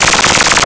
Arcade Movement 11.wav